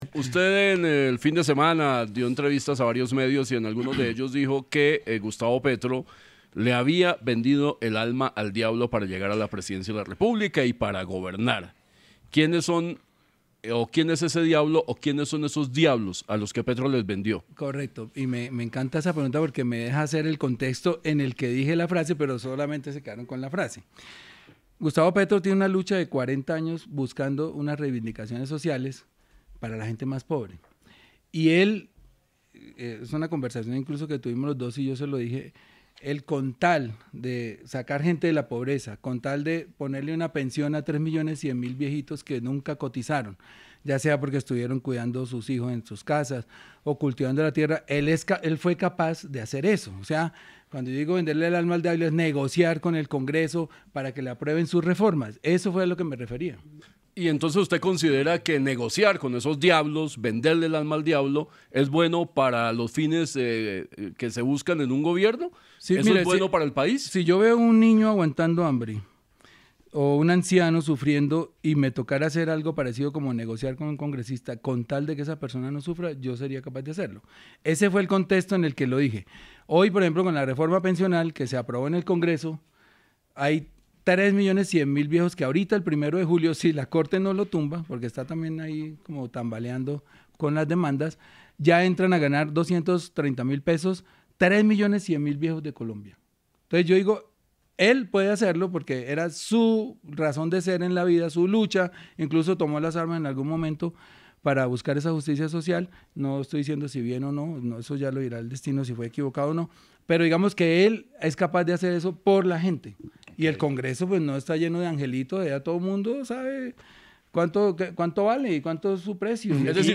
En una entrevista con Sin Anestesia de La Luciérnaga de Caracol Radio, el precandidato presidencial Gustavo Bolívar reconoció que, al igual que Gustavo Petro, estaría dispuesto a “venderle el alma al diablo” —en referencia a negociar con sectores políticos controversiales— si con ello lograra beneficios para los más necesitados.